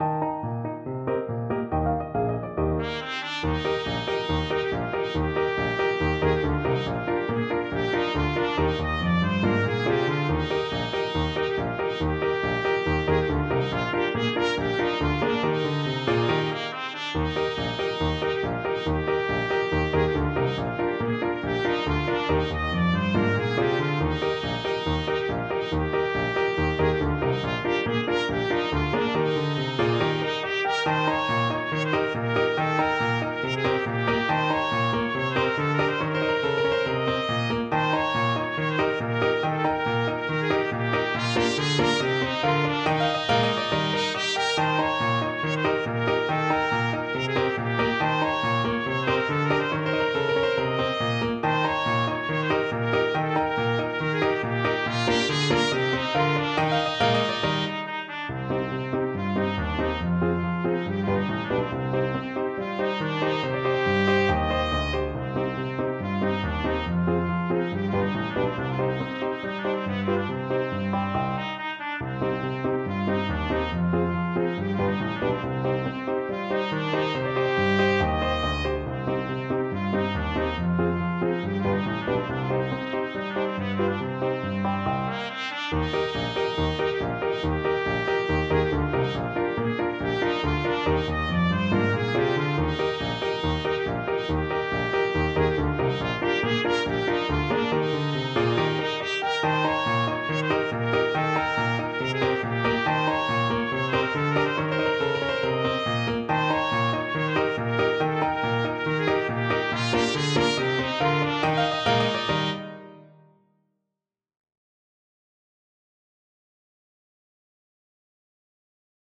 Traditional Music of unknown author.
Allegro =c.140 (View more music marked Allegro)
2/4 (View more 2/4 Music)
world (View more world Trumpet Music)